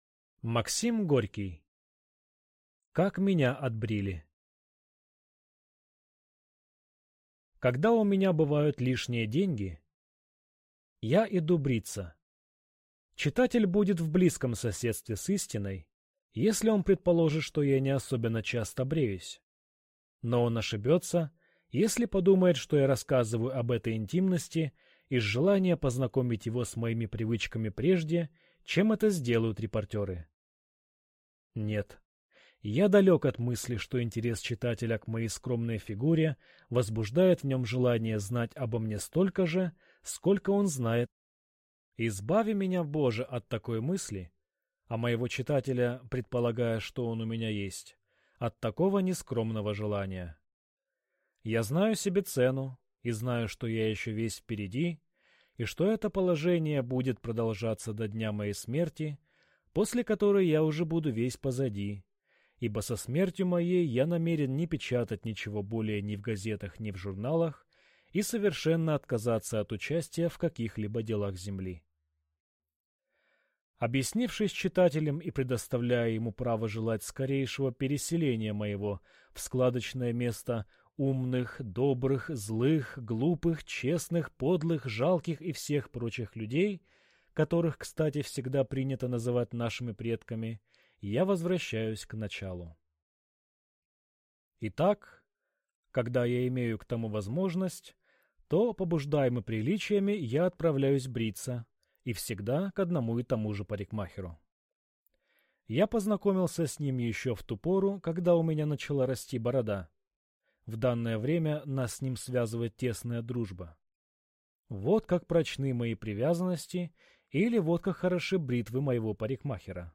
Аудиокнига Как меня отбрили…